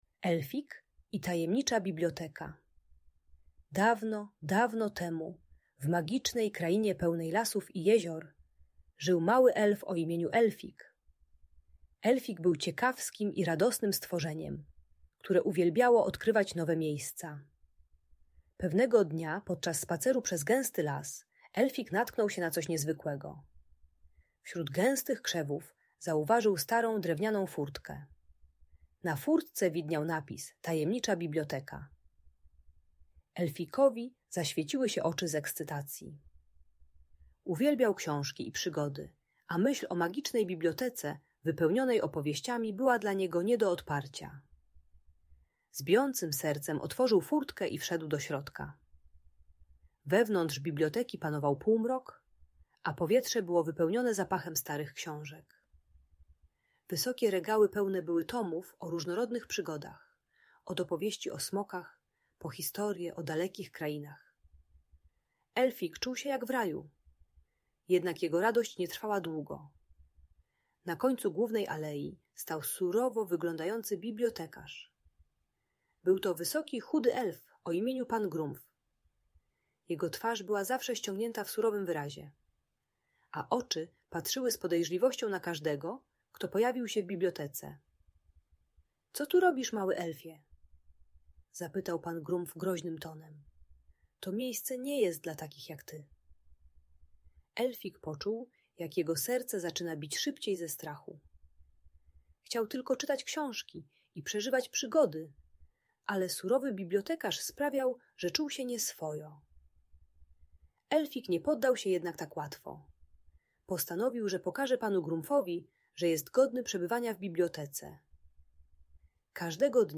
Elfik i Tajemnicza Biblioteka - Audiobajka